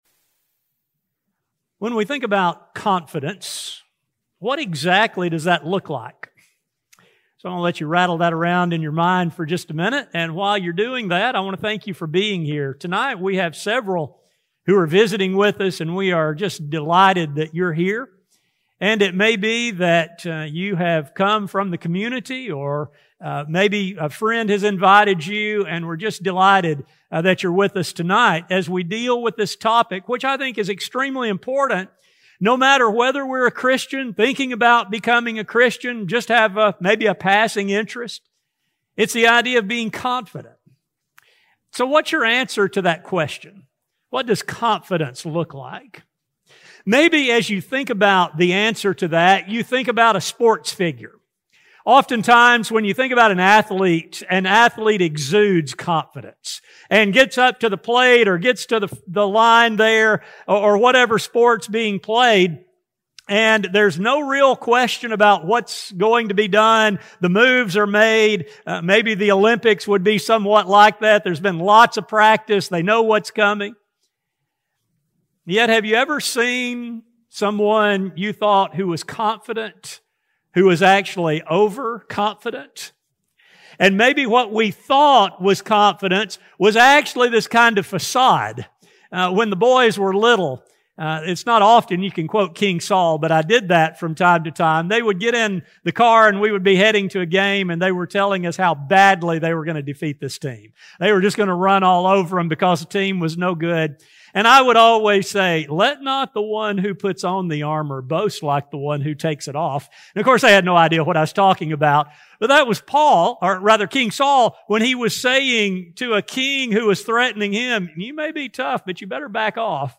This study will explain how one becomes a Christian as well as how a Christian can maintain confidence throughout his or her walk on this earth. A sermon recording